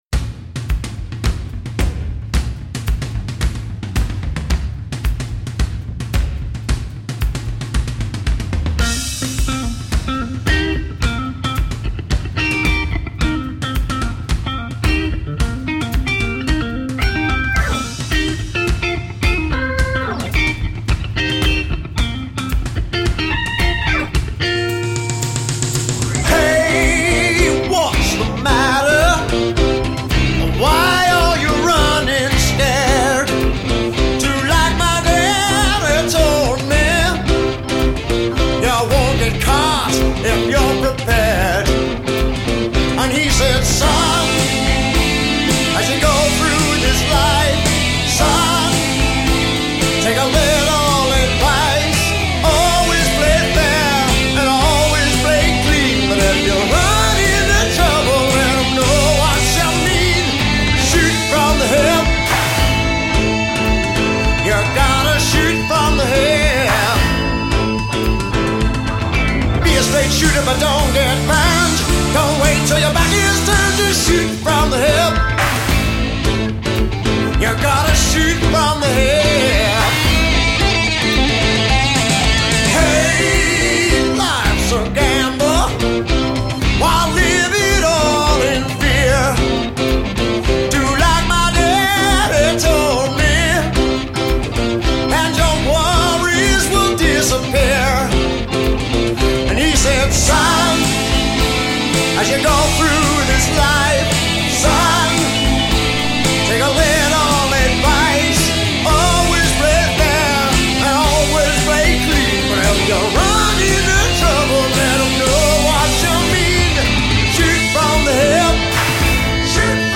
guitar
vocal